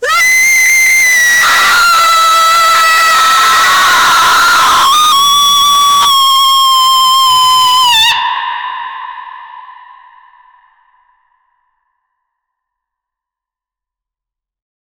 Royalty-free female-voices sound effects
a-female-ghost-shouting-a-p4iniu6g.wav